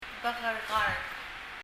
bekerekard[bɛk(g)ɛrɛk(g)ərð]red
パラオ語の音素」にあるように、「母音にはさまれた k は [g]音になる」というルールにしたがって、[g]音に聴こえます。